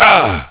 Worms speechbanks
Ooff3.wav